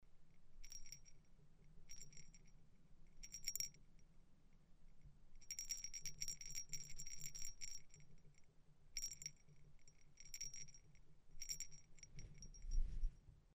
Sound recordings of original pellet bells and bells from the archaeological collection of the Slovak National Museum Bratislava, SK.
sound of original pellet bell, grave 412, Bratislava Devinska nova ves 0.21 MB
pellet_bell_grave_412_Devinska_Nova_Ves.MP3